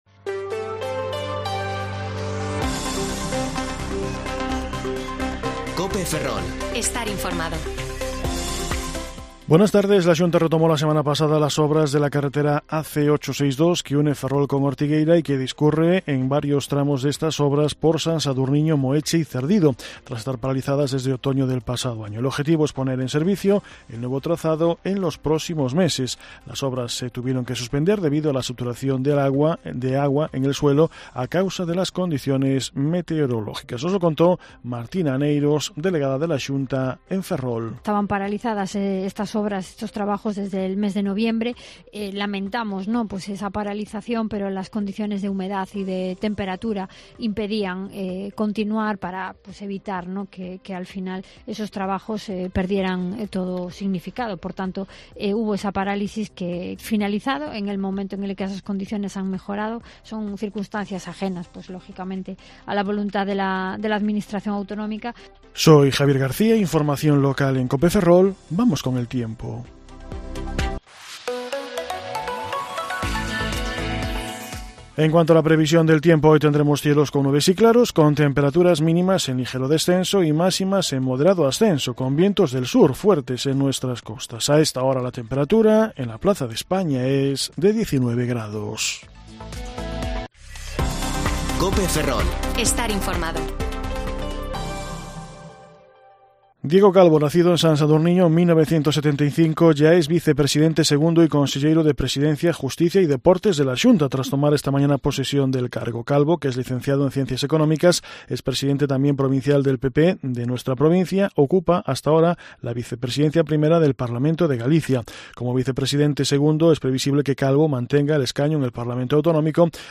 Informativo Mediodía COPE Ferrol 16/5/2022 (De 14,20 a 14,30 horas)